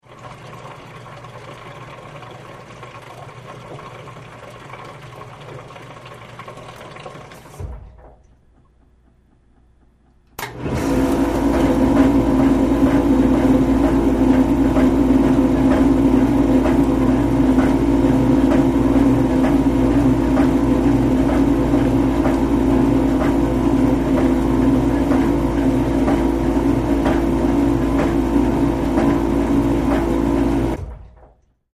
Dishwasher; Running 1; Dishwasher Filling And Washing; Stream Of Water Runs And Then Stops. Machine Then Switches Into Wash Mode. Close Perspective. Kitchen, Restaurant.